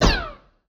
etfx_shoot_laser02.wav